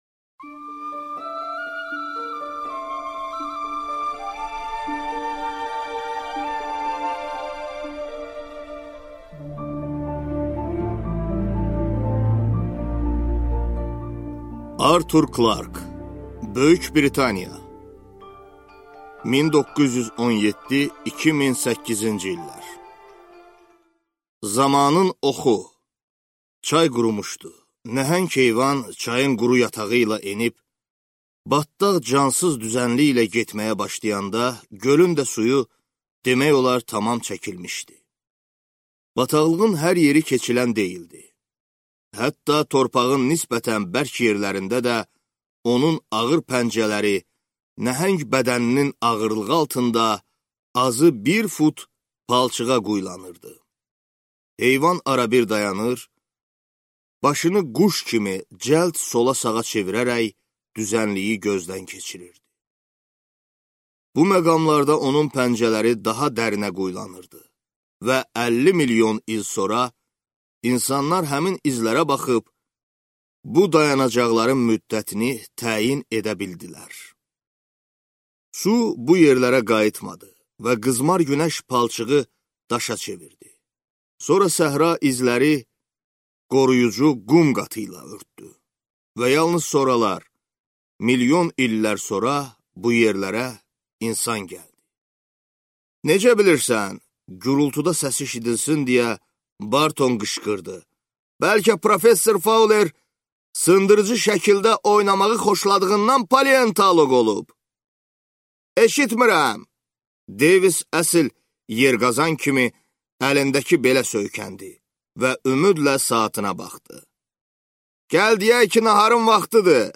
Аудиокнига Zamanın oxu | Библиотека аудиокниг